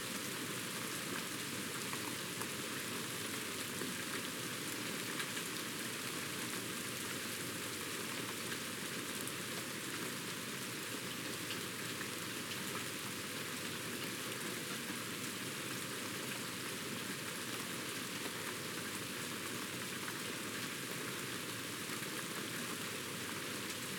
Soft Rain Loop 4.ogg